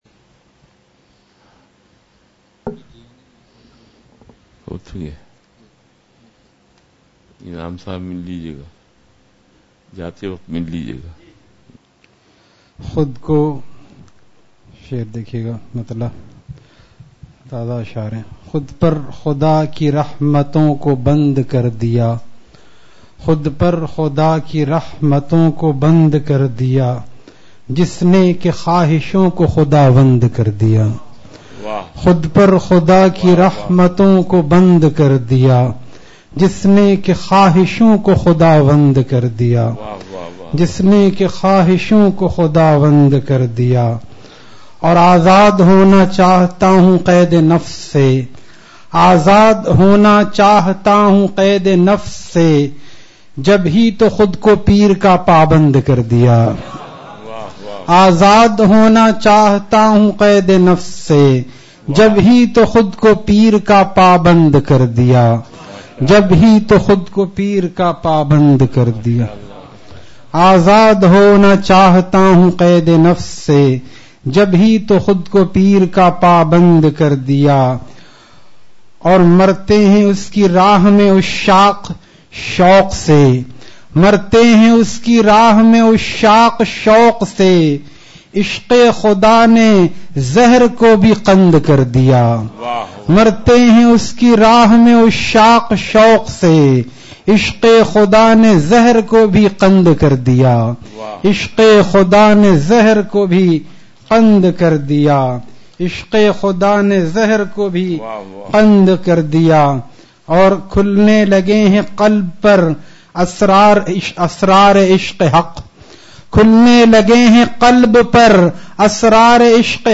اصلاحی مجلس کی جھلکیاں